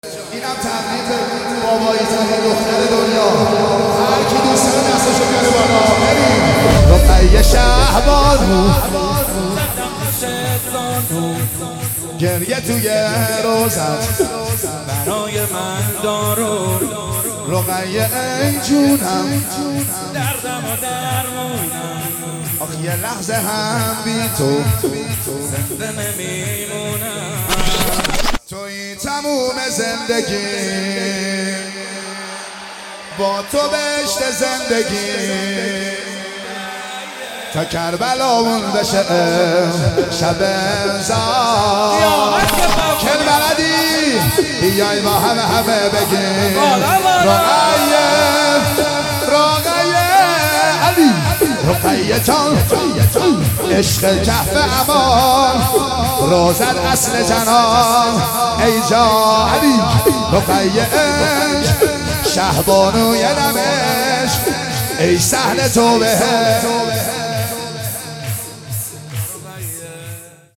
مولودی
هیئت مکتب البکا مشهد